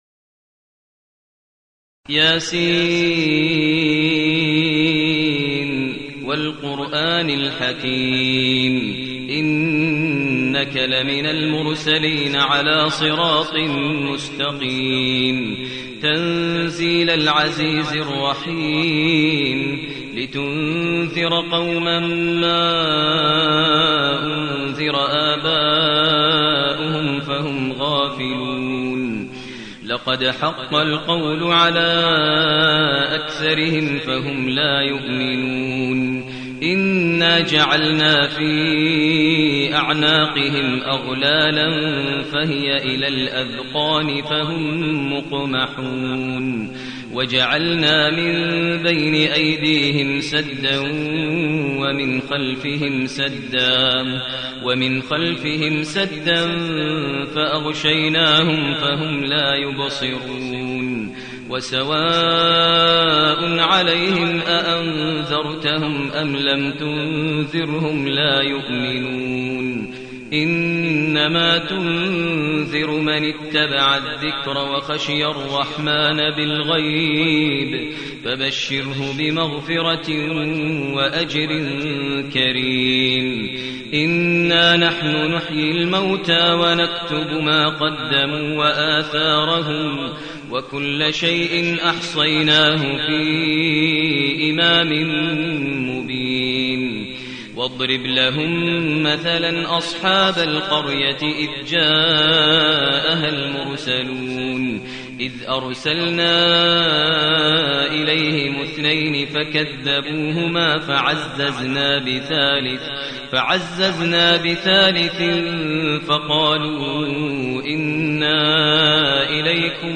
المكان: المسجد النبوي الشيخ: فضيلة الشيخ ماهر المعيقلي فضيلة الشيخ ماهر المعيقلي يس